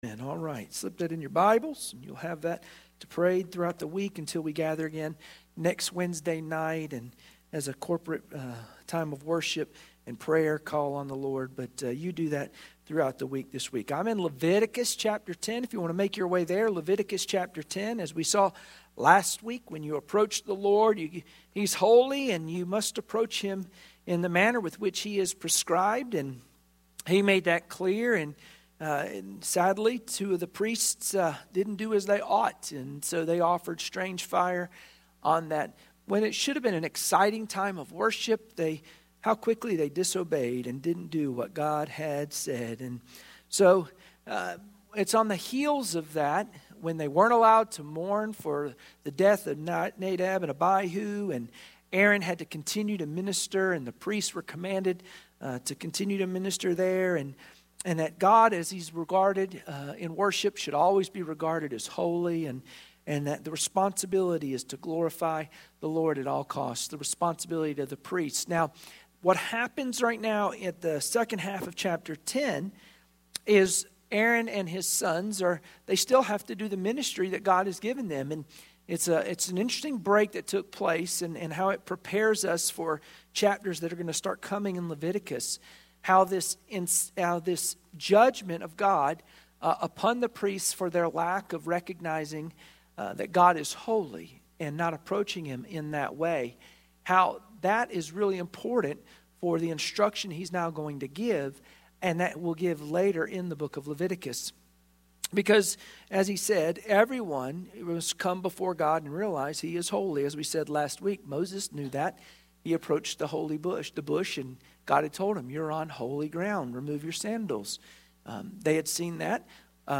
Wednesday Prayer Mtg Passage: Leviticus 10:9-11 Service Type: Wednesday Prayer Meeting Share this